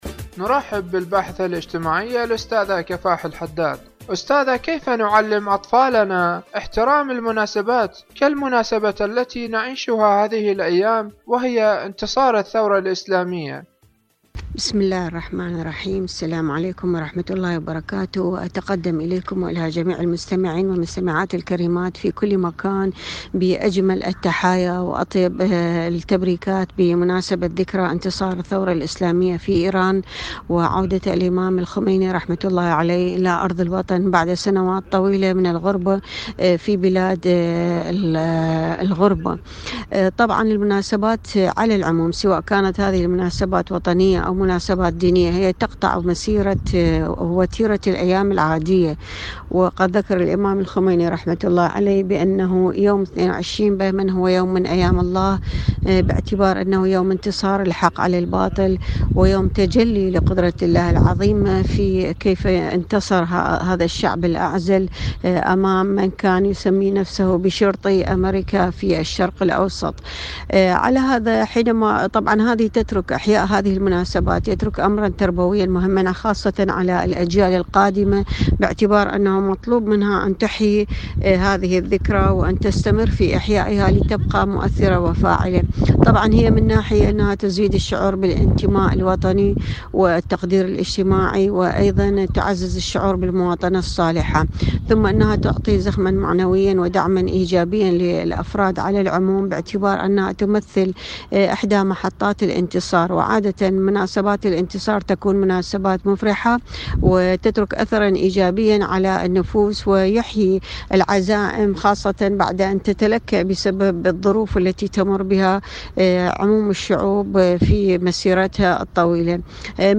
إذاعة طهران-عالم المرأة: مقابلة إذاعية